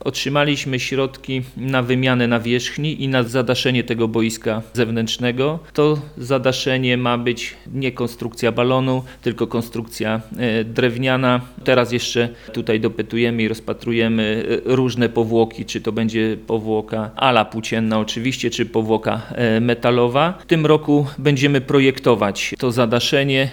Mówi prezydent Mielca Jacek Wiśniewski.